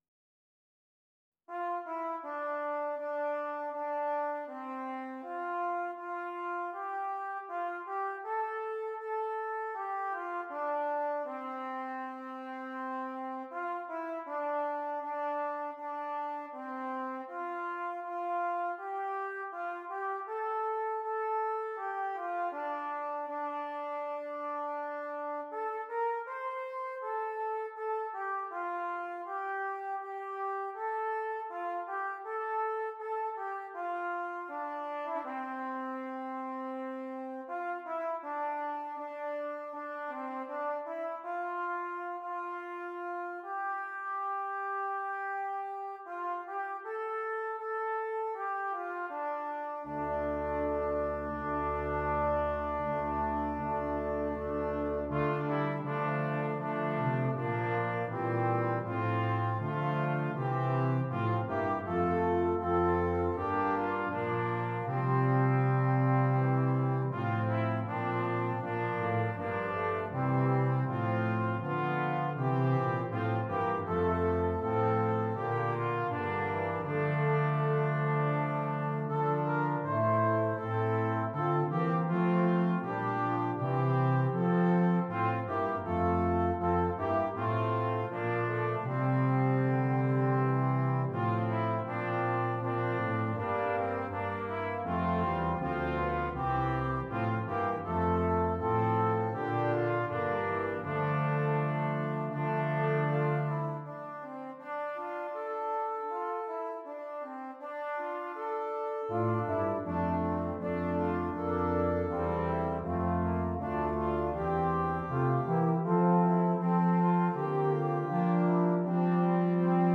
Brass Quintet
Traditional English Folk Song